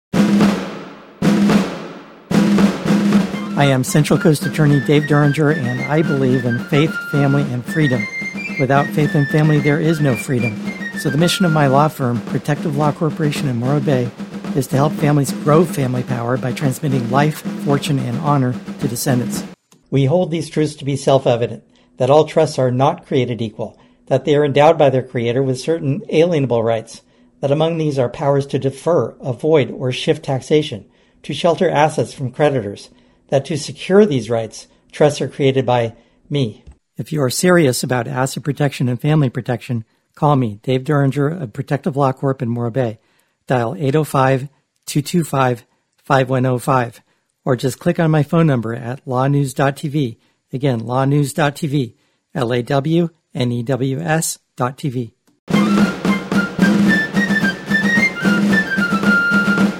Radio Ad 28 – All Trusts are NOT Created Equal
we-hold-with-music.mp3